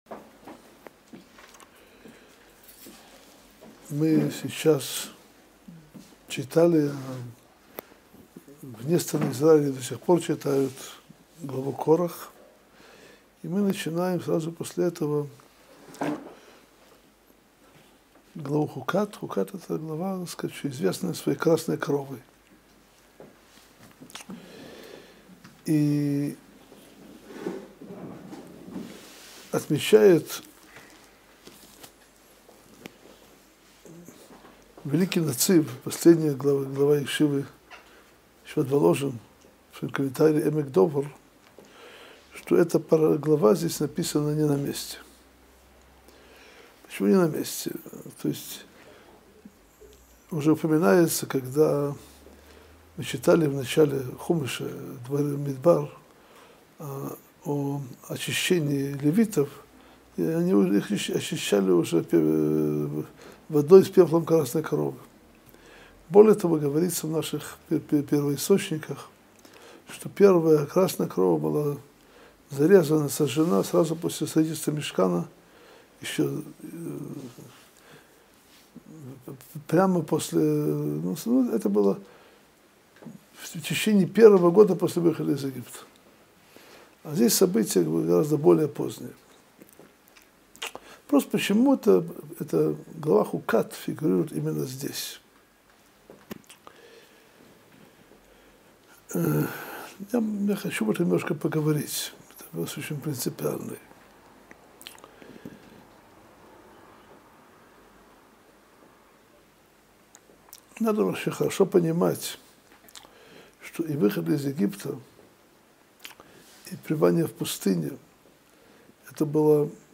Содержание урока: Почему глава Хукат написана не на своем месте? Когда была первая красная корова?